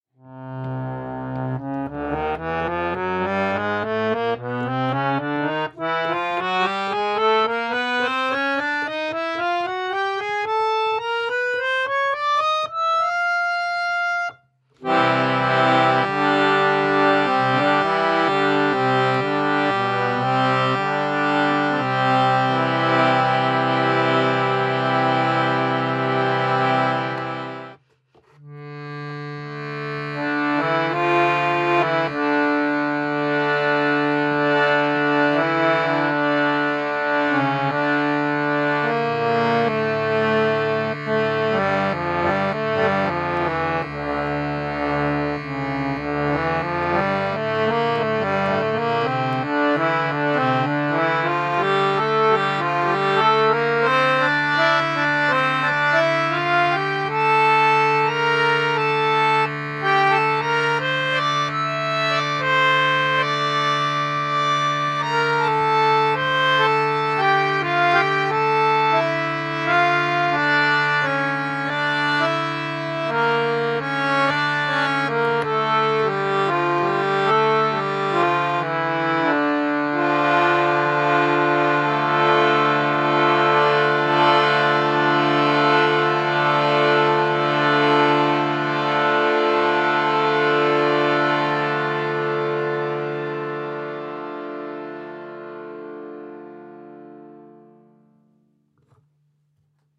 Der Klang ist sehr voluminös, lange ausschwingend und bezaubert mit seiner warmen Klangfarbe.
pro Ton zwei Zungen im mittleren und tiefen Register
5 Registerzüge (getrenntes oder gemeinsames Spielen der beiden Register + Tremoloregister)
4 schaltbare Bordunzüge (c, d, g, a)